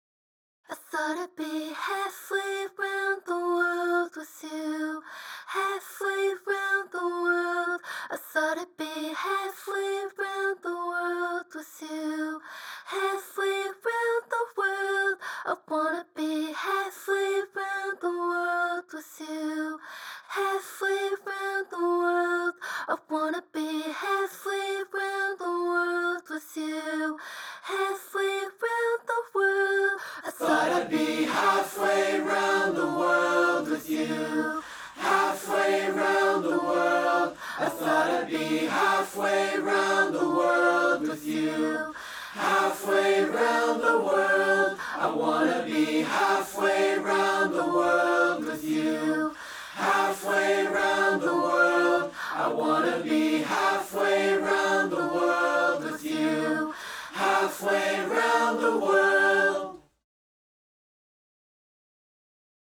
acapella